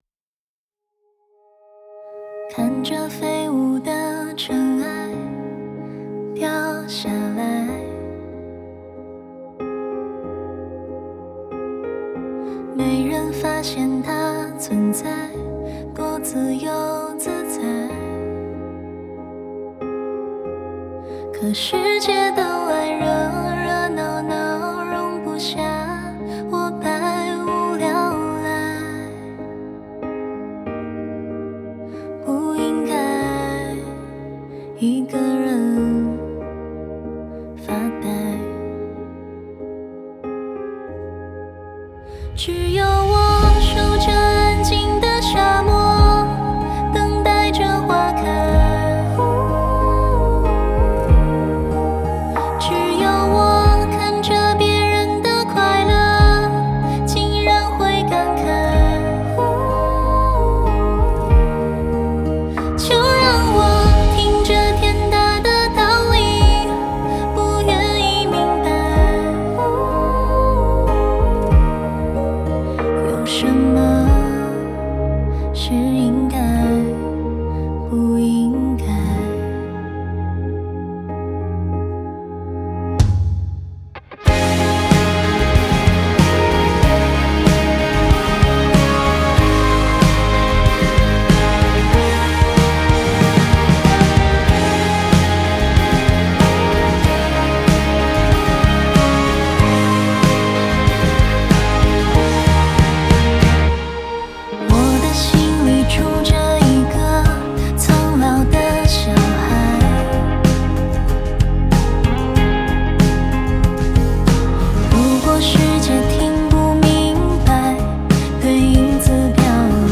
(该作品已获得官方翻唱授权)